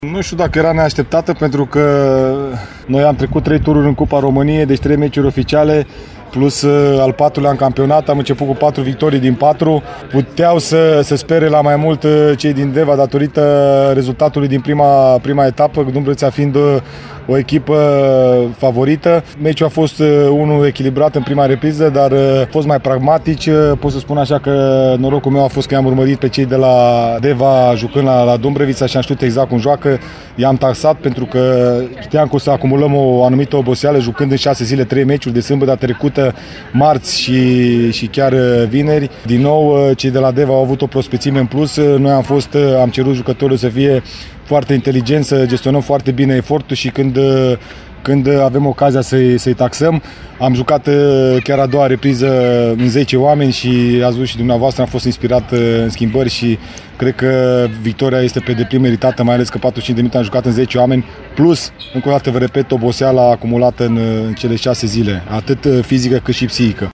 Ascultăm reacții culese după joc de colegul nostru